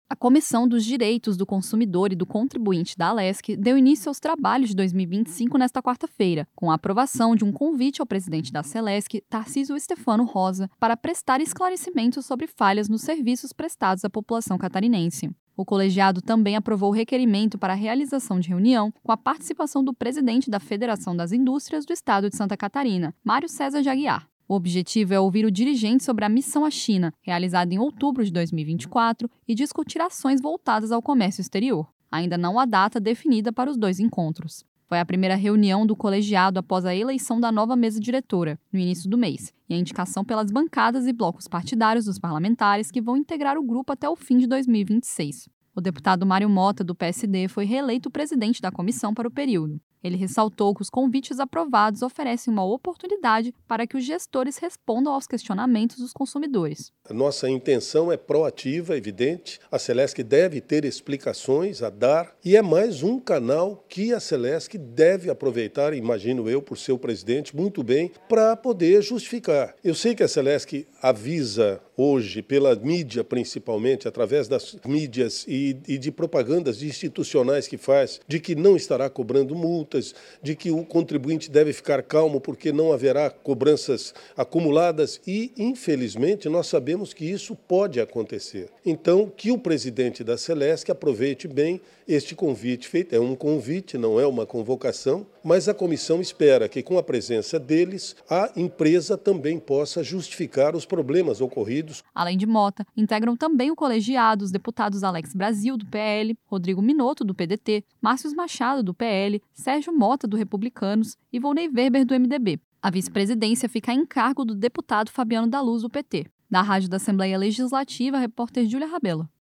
Entrevista com:
- deputado Mario Motta (PSD), presidente da Comissão dos Direitos do Consumidor e do Contribuinte.